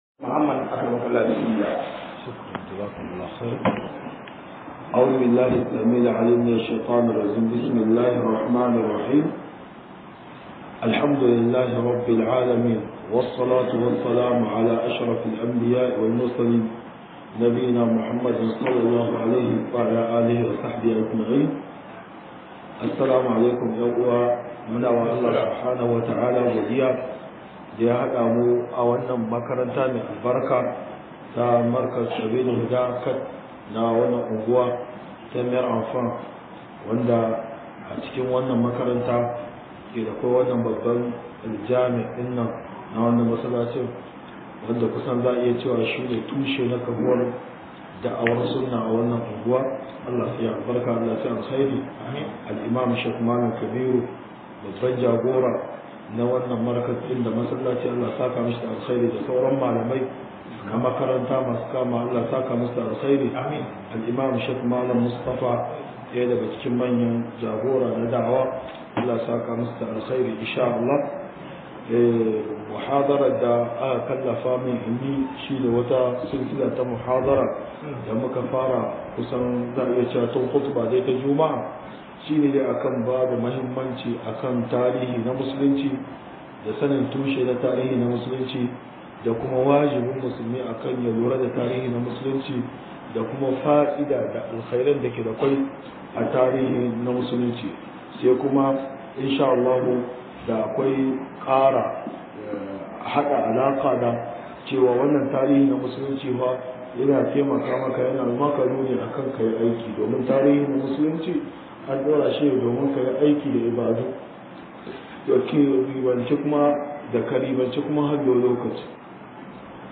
001-TUSHEN TARIHIN SHEKARAR MUSLUNCI - MUHADARA